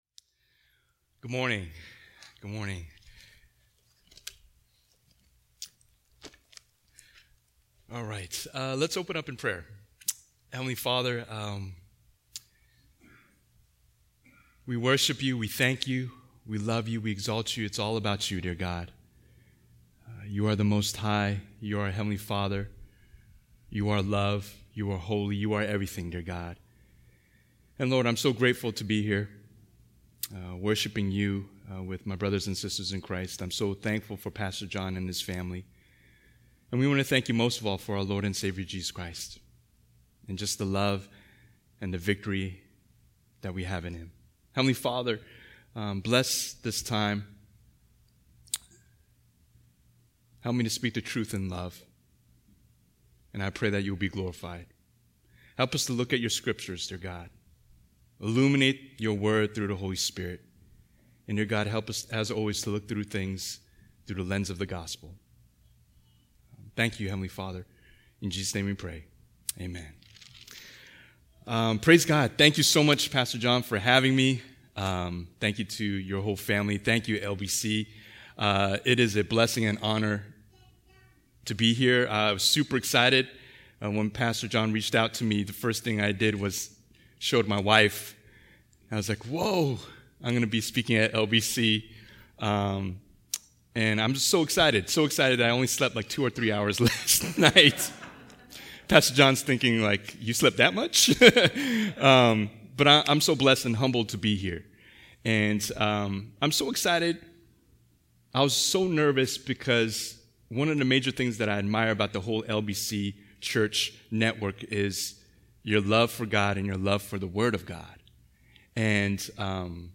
2021 (Sunday Service)Bible Text